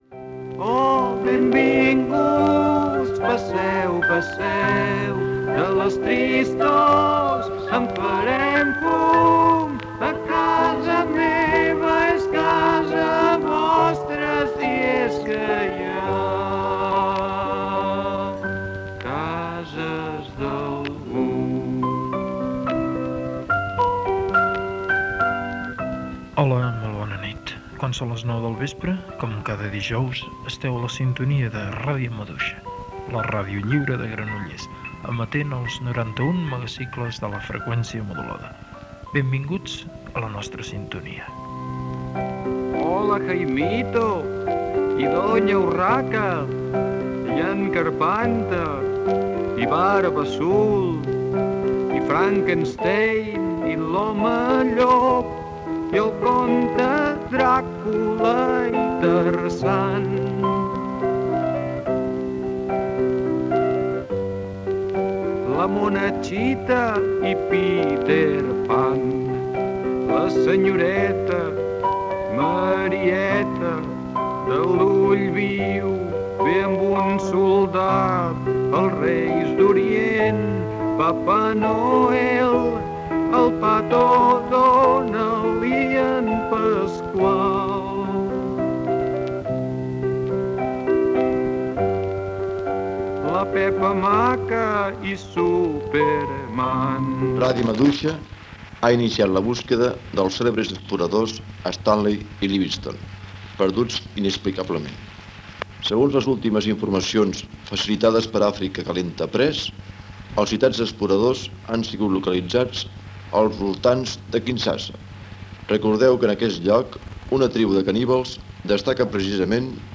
Identificació i benvinguda a l'emissió amb el tema «Qualsevol nit pot sortir el sol» de Jaume Sisa. Ficció sonora: "Ràdio Maduixa a la recerca dels exploradors Livingstone i Stanley".
Ficció
FM